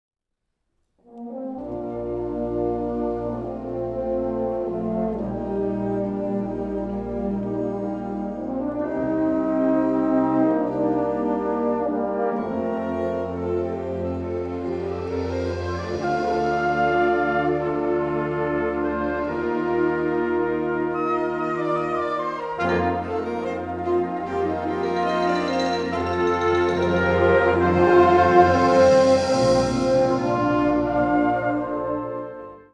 Categorie Harmonie/Fanfare/Brass-orkest
Subcategorie Concertmuziek
Bezetting Ha (harmonieorkest)
Pure romantiek en kippenvel!